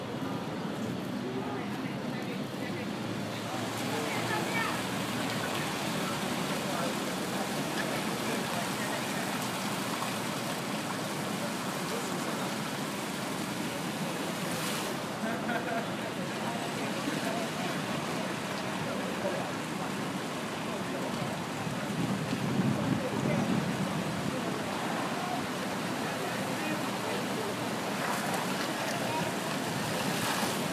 Steps of Metropolitan Museum of Art